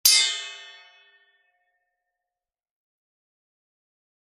Metal Pan Ping Hit, Type 7